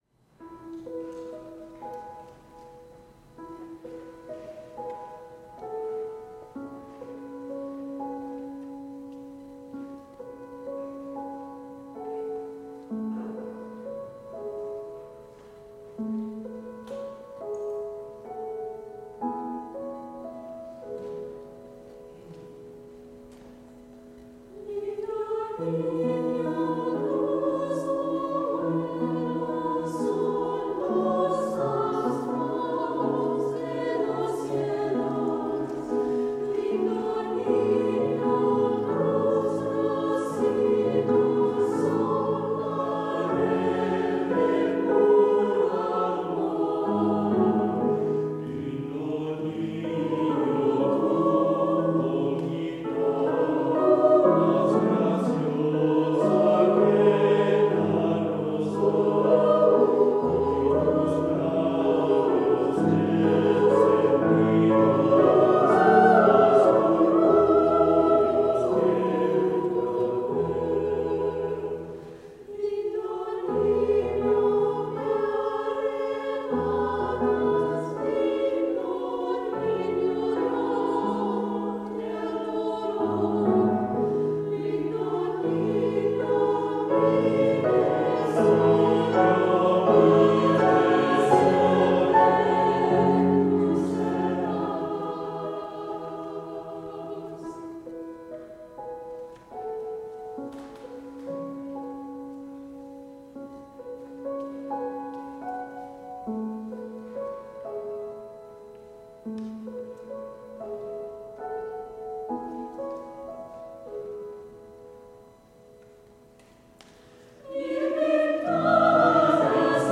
SAB + Piano 3’30”